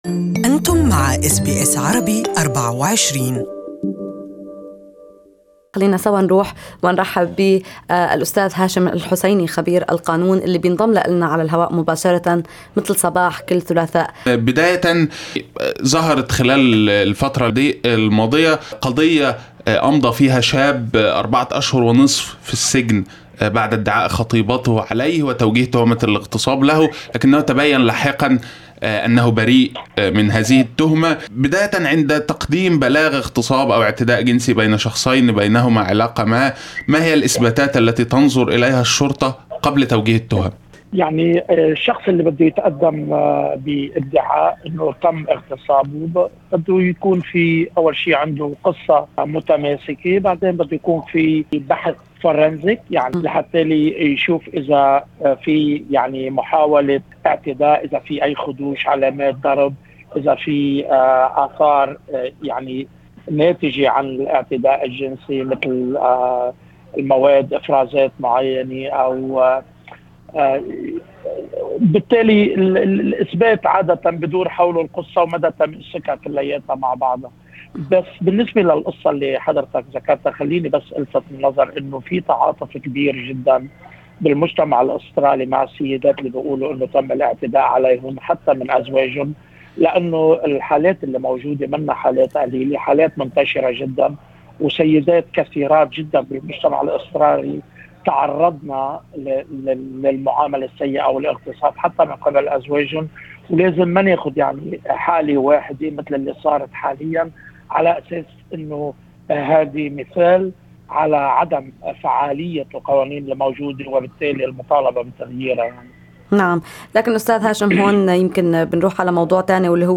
للتعليق على الموضوع ومعلومات اكثر عن كيفية اثبات الاعتداء الجنسي استمعوا للقاء المحامي